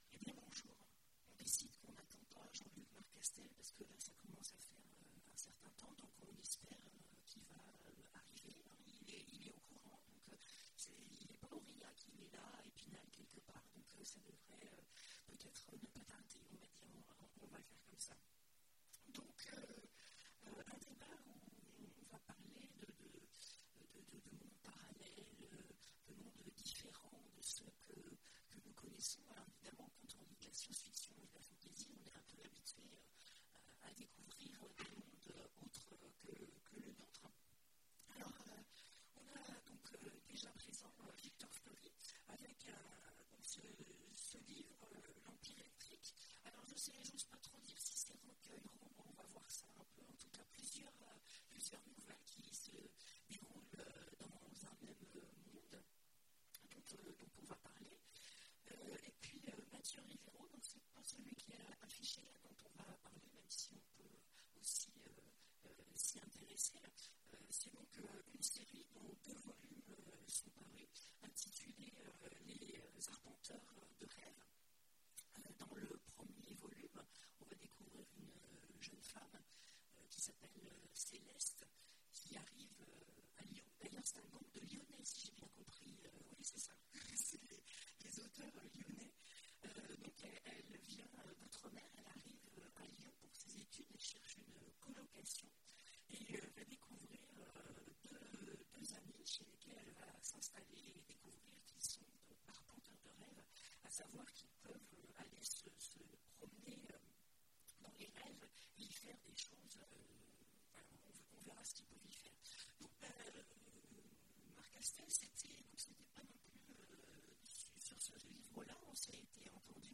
Imaginales 2017 : Conférence Sociétés étranges… et mondes parallèles !